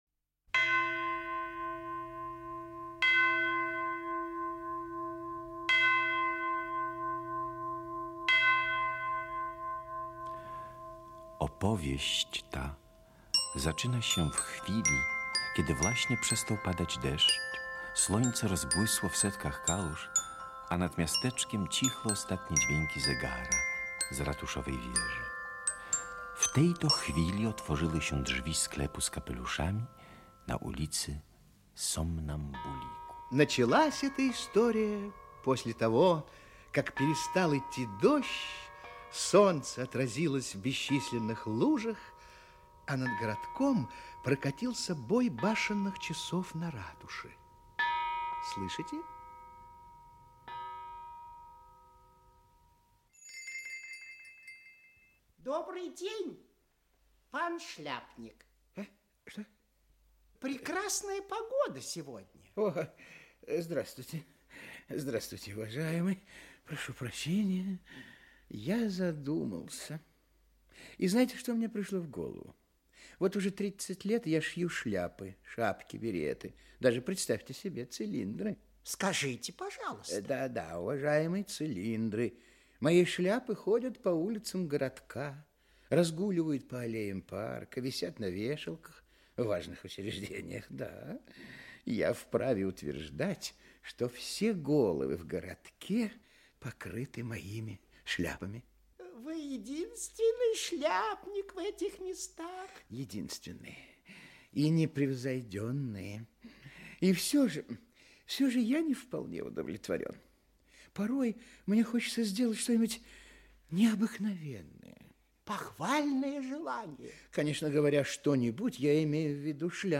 Аудиокнига Необыкновенная шляпа | Библиотека аудиокниг
Aудиокнига Необыкновенная шляпа Автор Кристина Бронжевская Читает аудиокнигу Актерский коллектив.